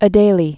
(ə-dālē)